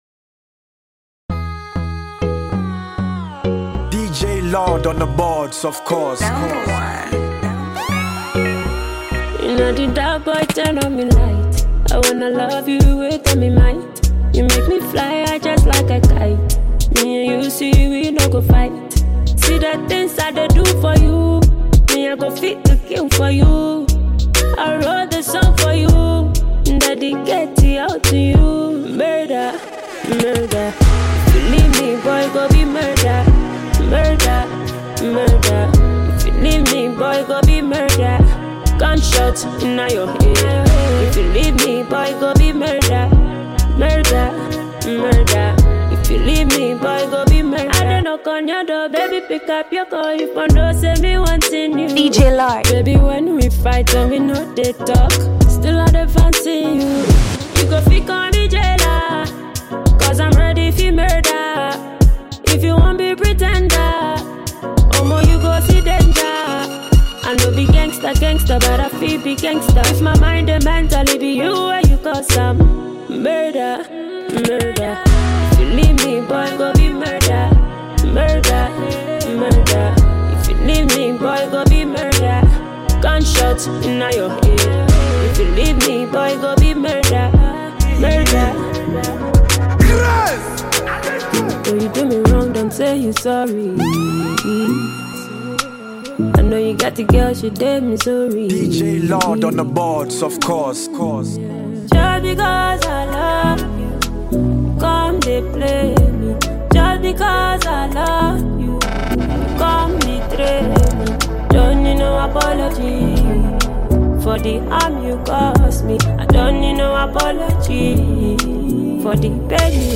Award-winning Ghanaian disc jockey